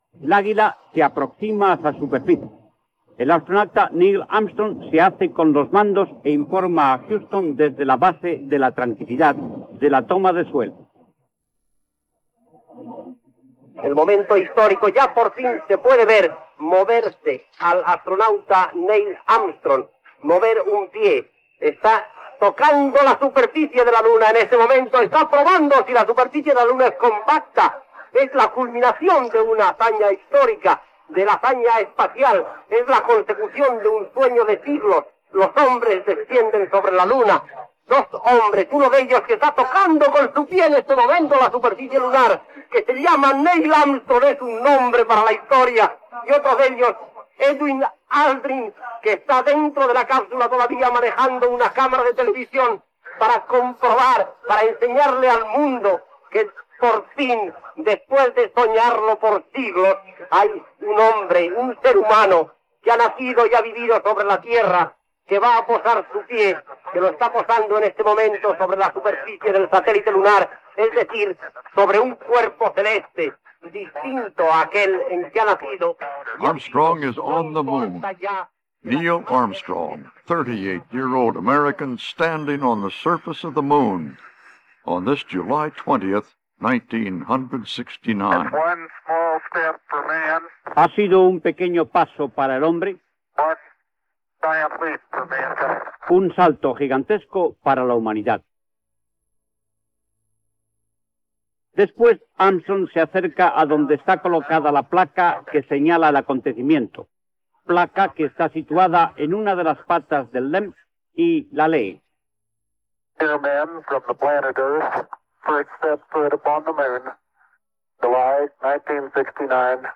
Fragments de la transmissió, des d'Huston (EE.UU.), del moment que l'astronauta de la NASA Neil Armstrong, comandant de l'Apol·lo 11, va trepitjar la superfície de la Lluna.
Informatiu
Extret del programa "Documentos" de RNE emès el 29 de setembre de 2001